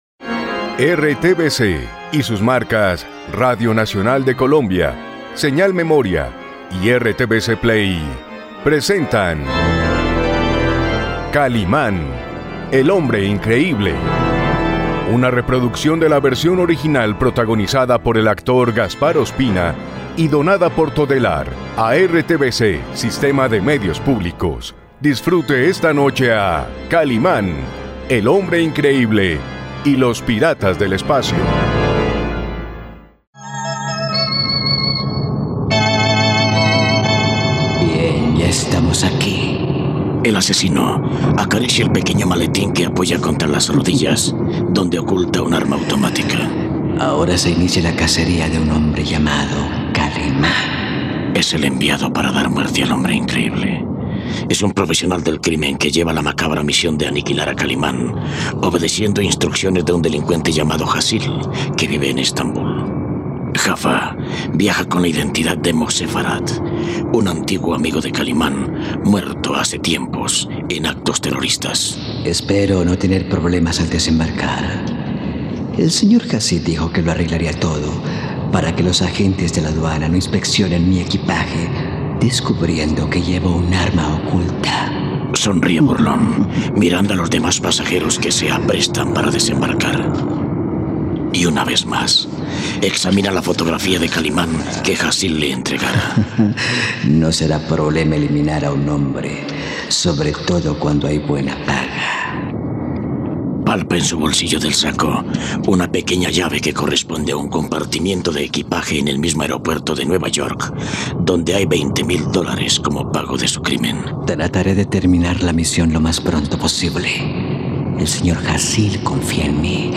..Kalimán intenta identificar al impostor al llegar a aeropuerto. No te pierdas ninguno de los capítulos de ésta increíble radionovela de Radio Nacional, aquí en RTVCPlay.